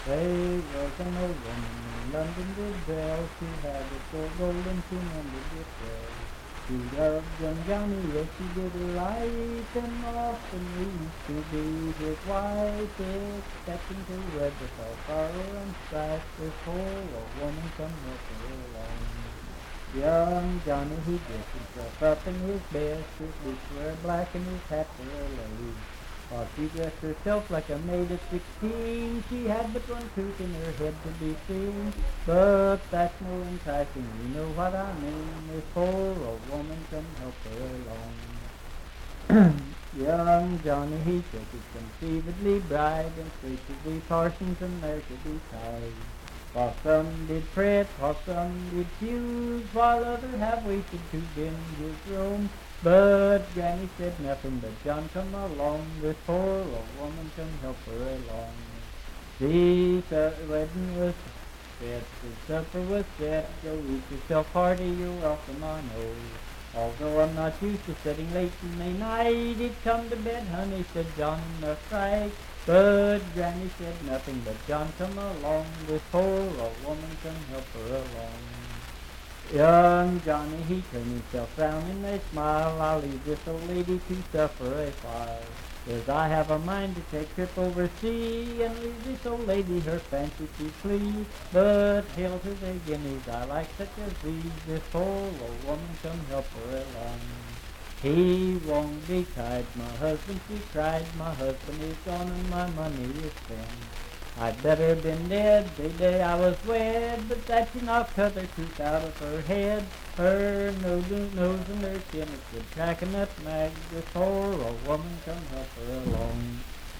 Unaccompanied vocal music
in Riverton, W.V.
Verse-refrain 6(6w/R).
Voice (sung)